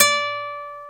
NYLON  D4.wav